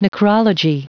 Prononciation du mot : necrology
necrology.wav